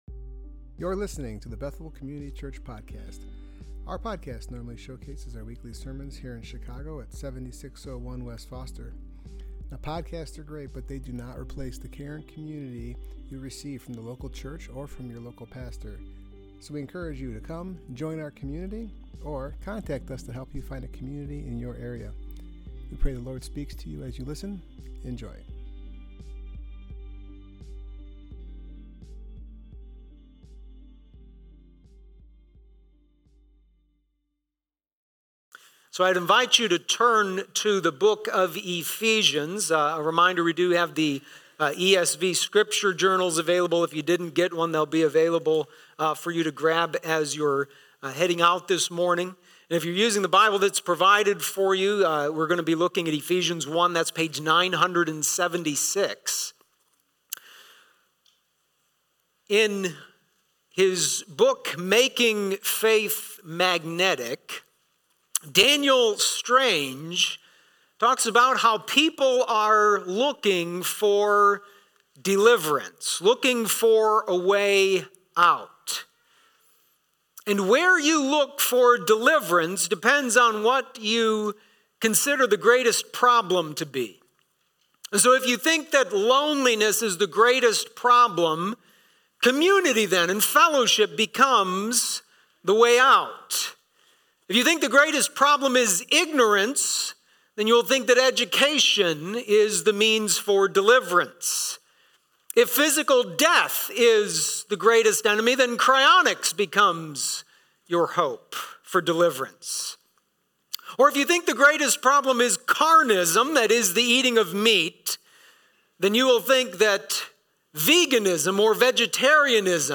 Union With Christ Passage: Ephesians 1:7-10 Service Type: Worship Gathering « Promised